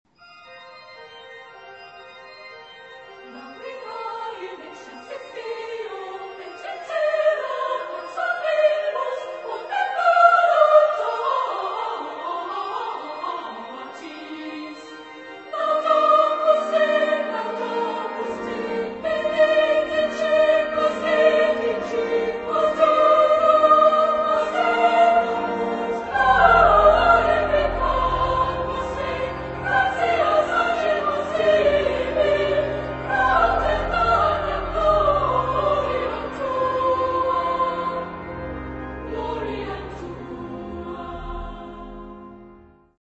Genre-Style-Forme : Messe ; Sacré
Caractère de la pièce : solennel
Type de choeur : SATB  (4 voix mixtes )
Instruments : Orgue (1)